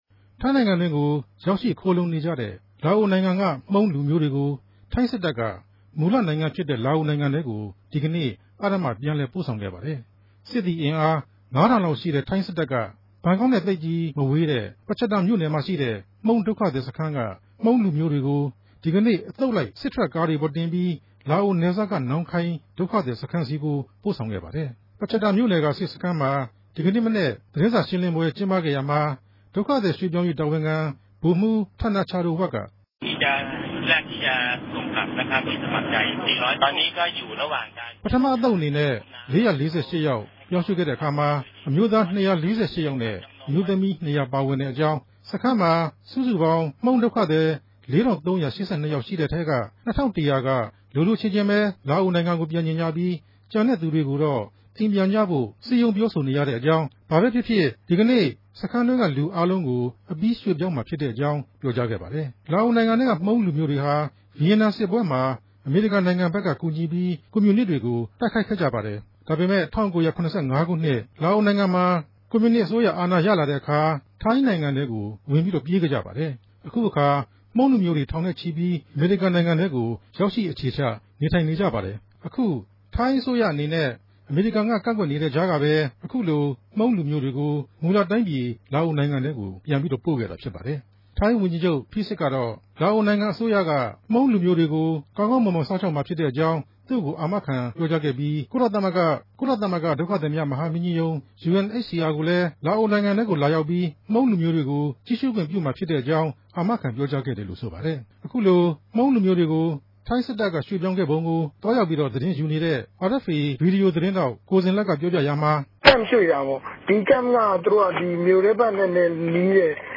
သတင်းပေးပိုႚခဵက်။